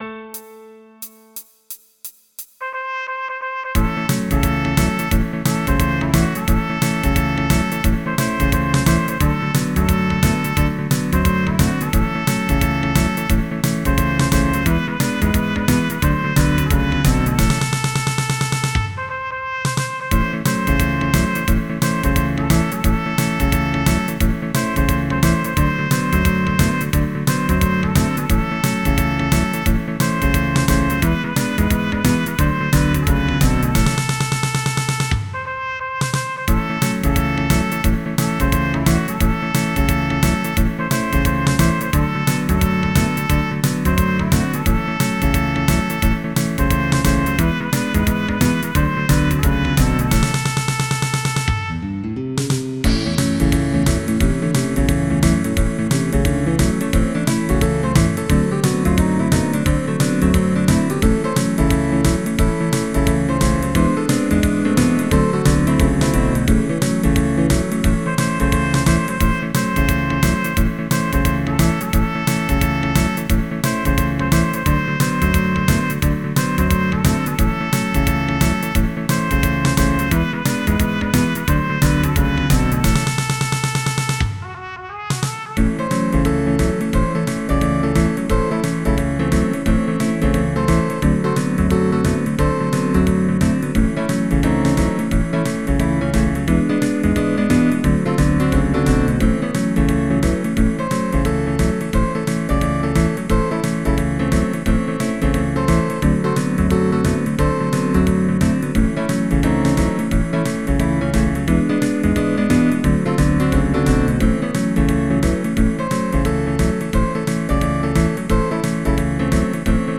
Some old MIDI version